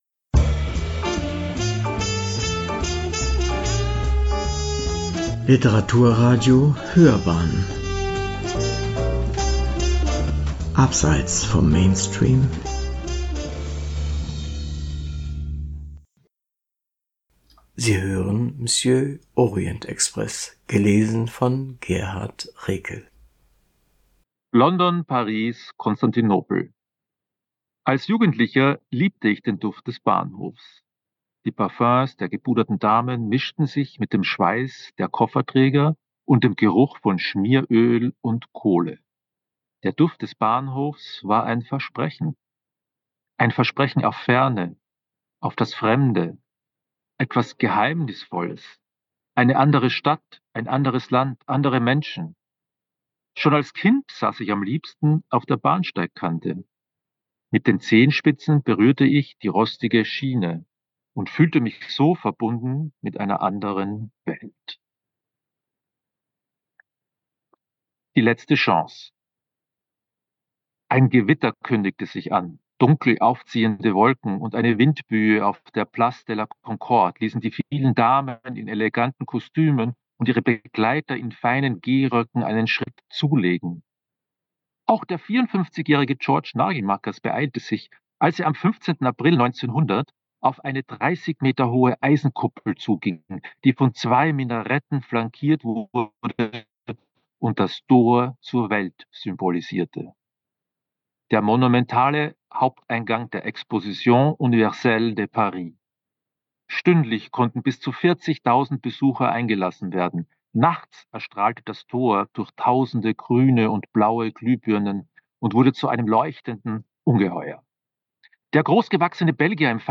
EJEB-Orient-express-Lesung-GRK-upload-.mp3